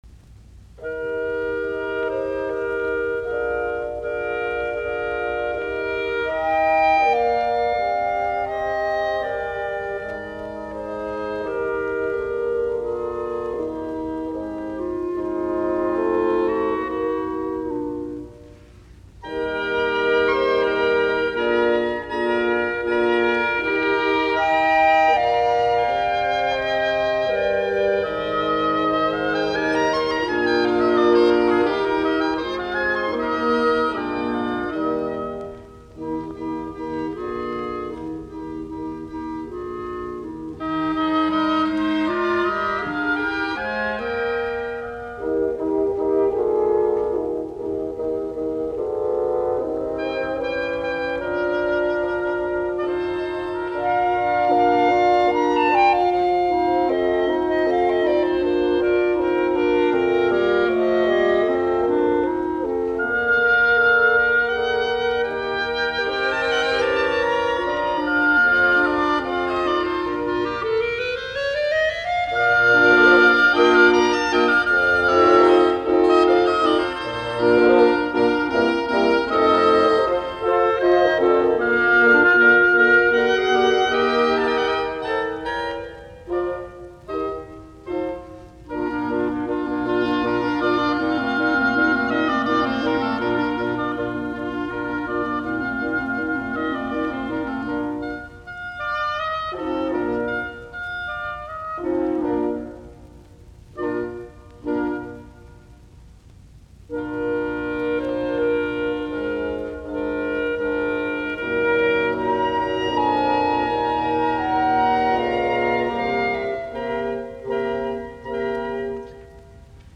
Serenadit, puhaltimet, KV384a = KV388, c-molli